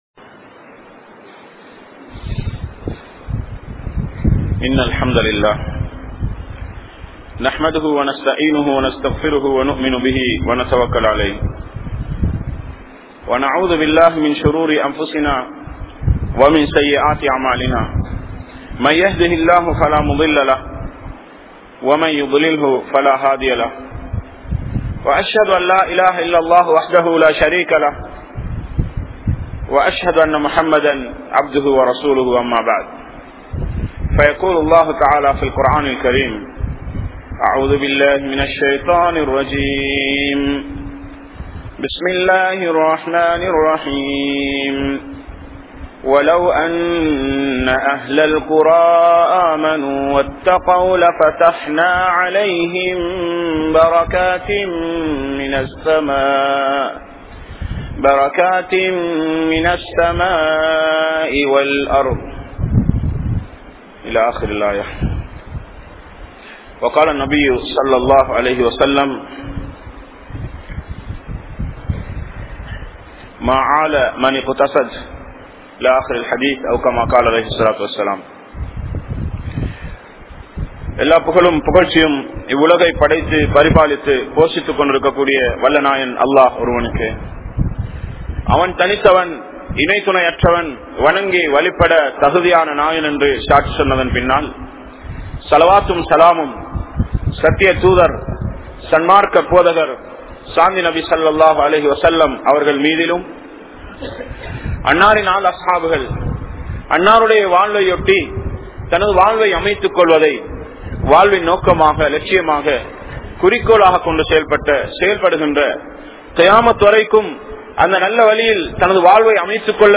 Barakath Entraal enna?(பரக்கத் என்றால் என்ன?) | Audio Bayans | All Ceylon Muslim Youth Community | Addalaichenai